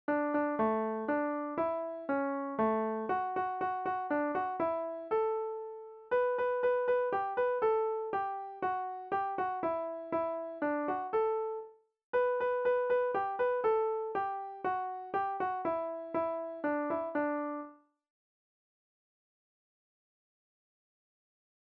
Nahrávky živých muzikantů si můžete poslechnout u písniček Vločka a Jinovatka, u ostatních si můžete poslechnout zatím jen melodie generované počítačem 🙂